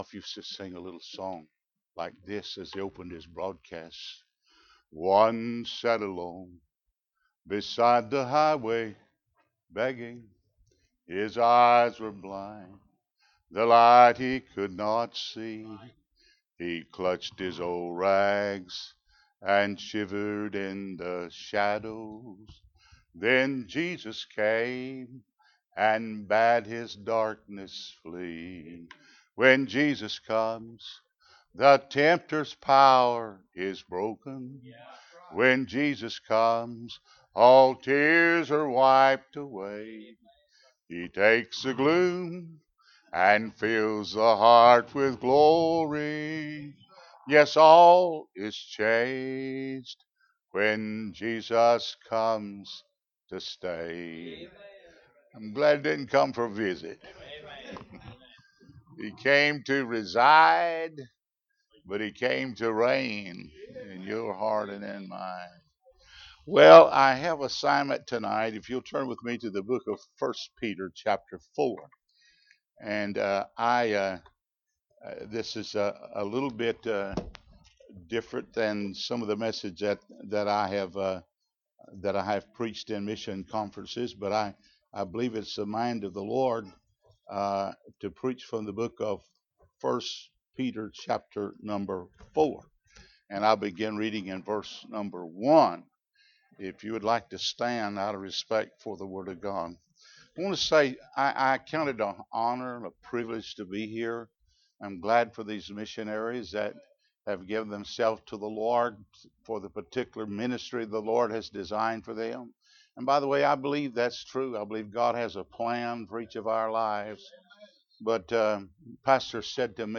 1 Peter 4:1-11 Service Type: Mission Conference Bible Text